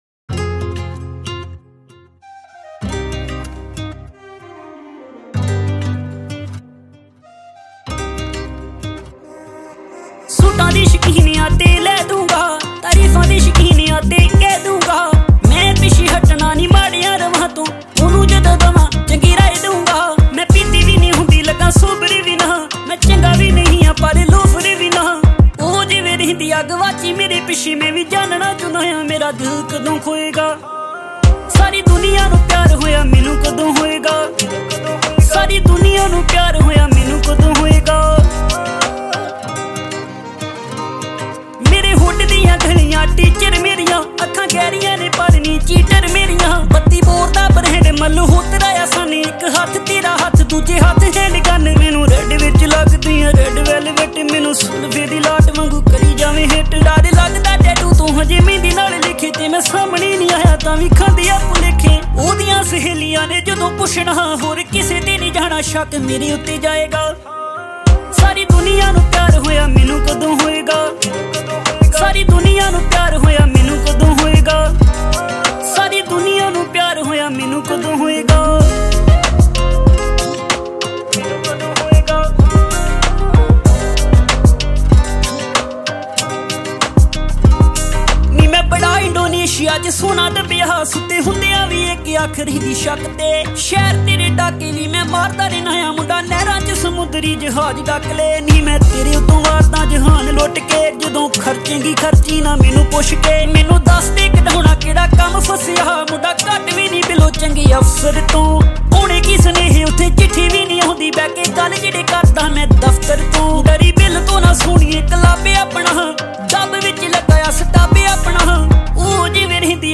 Releted Files Of Latest Punjabi Song